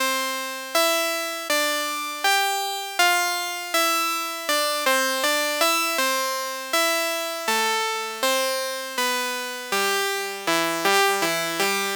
Track 16 - E-Harpsichord 01.wav